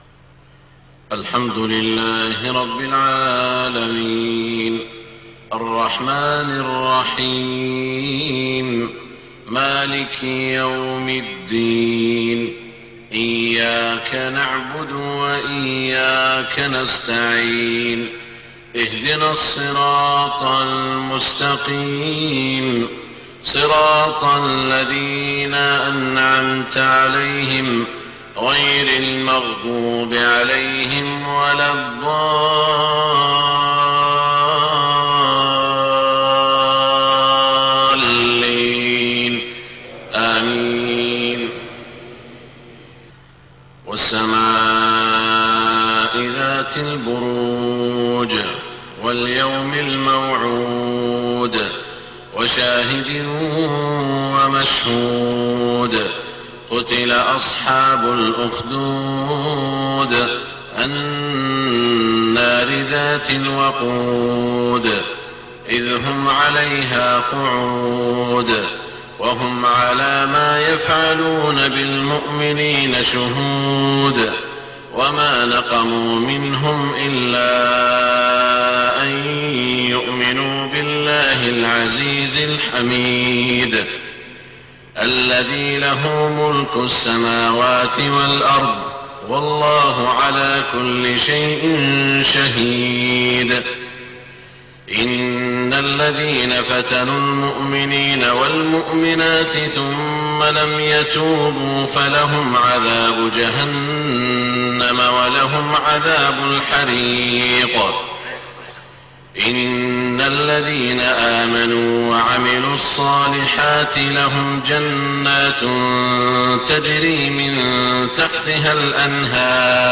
صلاة الفجر 8-5-1429هـ من سورتي البروج و الفجر > 1429 🕋 > الفروض - تلاوات الحرمين